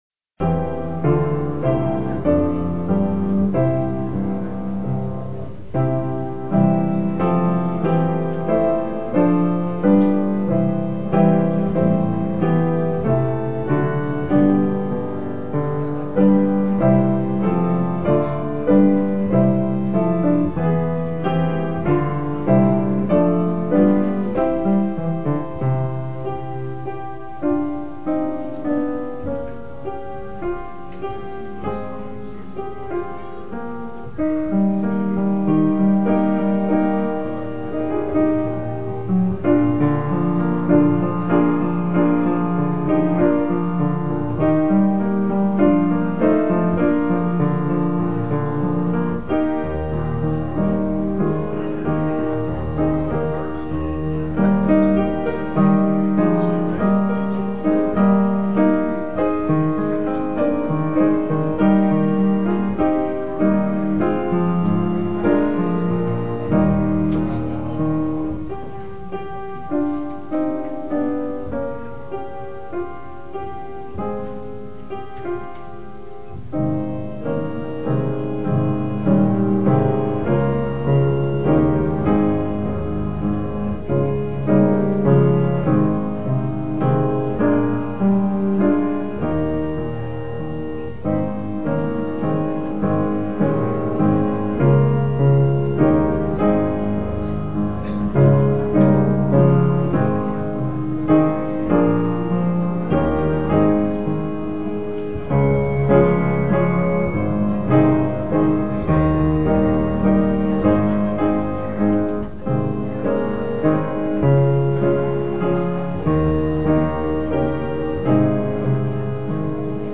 PLAY Heroes of Faith, Part 3, Nov 5, 2006 Scripture: Hebrews 11:32-40. Scripture Reading
" "Faith of Our Fathers" instrumental prelude
piano
organ